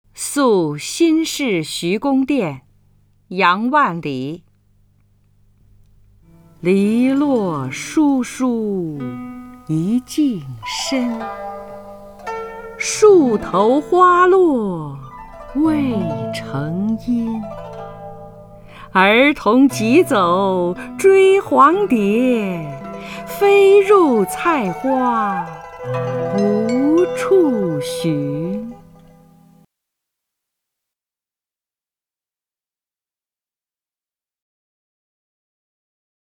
首页 视听 名家朗诵欣赏 张筠英
张筠英朗诵：《宿新市徐公店》(（南宋）杨万里)